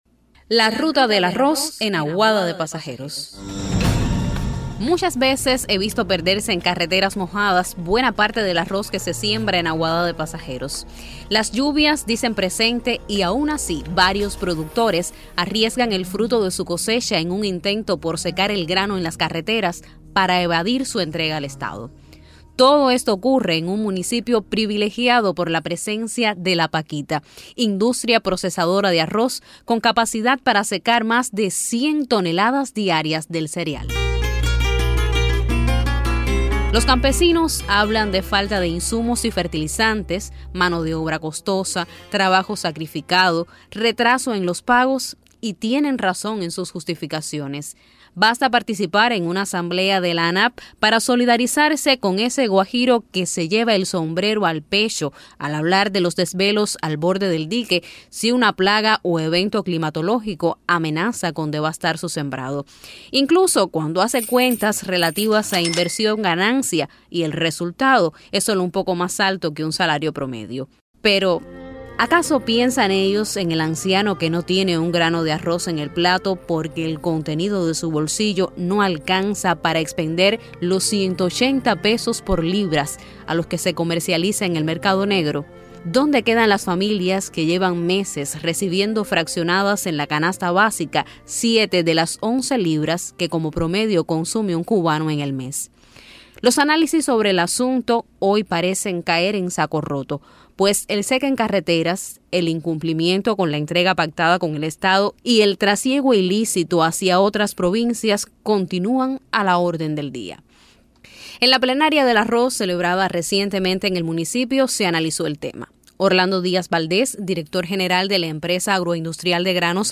El Sitio Web de Radio Ciudad del Mar propone escuchar algunos de los trabajos que concursan en el Festival Provincial de la Radio en Cienfuegos: Categoría Géneros informativos: Comentario La ruta del arroz en Aguada de Pasajeros.